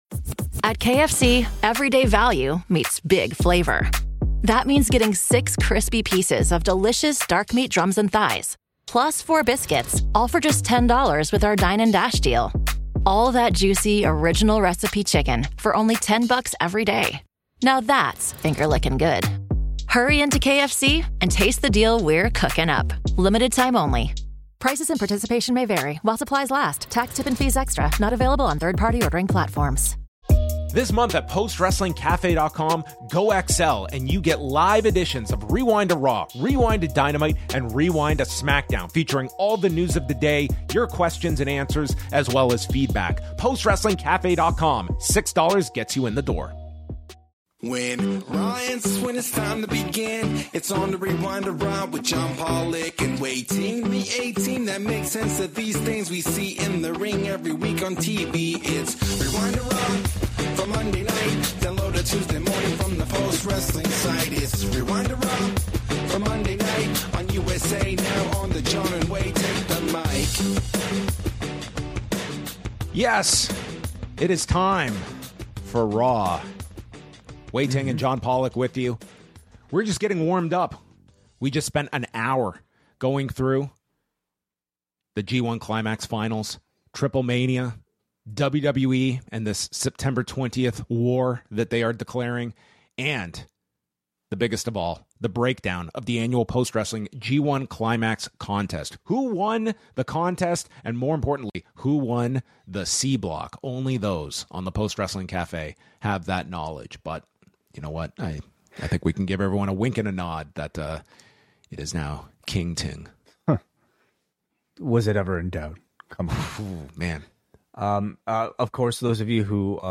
Patron calls and feedback from the POST Wrestling Forum